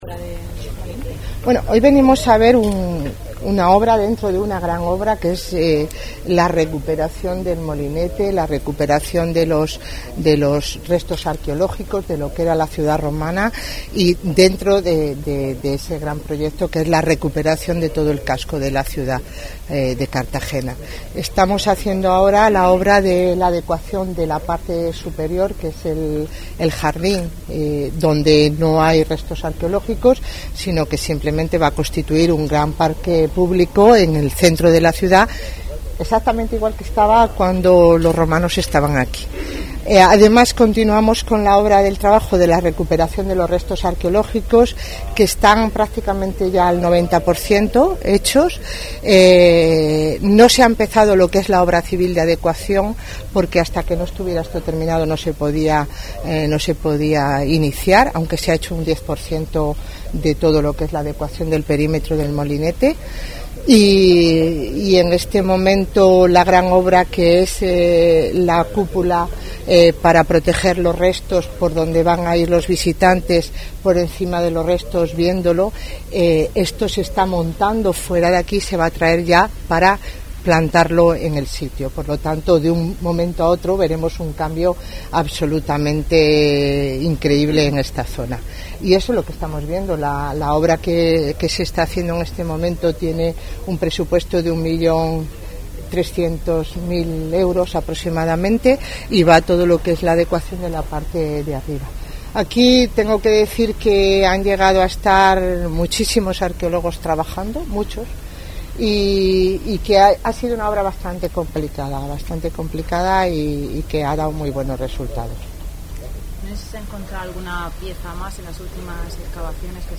Visita de la alcaldesa a las obras del Molinete